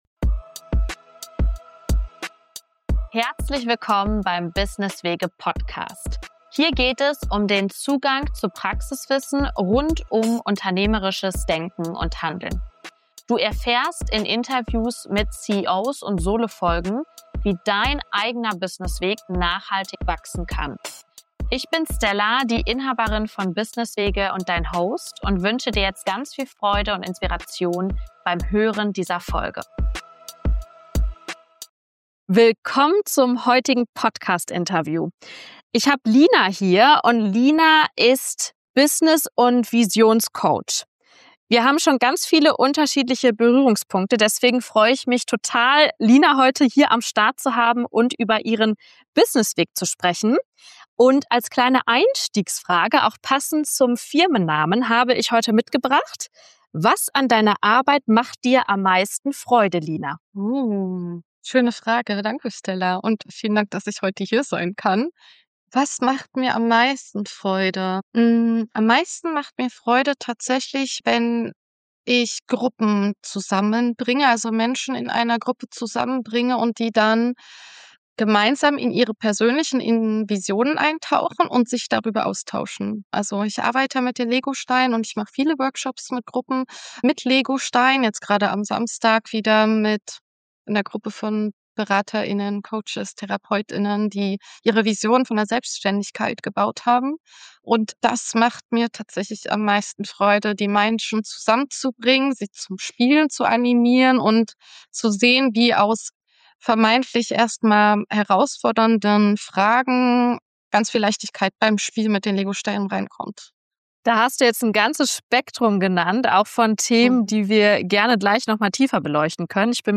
Dabei wird deutlich, wie wichtig Freiheit, Flexibilität und eine bewusste Definition von Erfolg für nachhaltige Freude an der Arbeit sind. Wir sprechen außerdem über Glaubenssätze rund um Arbeit und Leistung, über innere Antreiber, systemisches Coaching und die Rolle von Gruppenarbeit als Raum für Inspiration, Unterstützung und Wachstum. Auch Themen wie Remote-Arbeit, Teamdynamiken und interkulturelle Perspektiven auf Arbeits- und Lebensmodelle spielen eine zentrale Rolle in diesem Gespräch.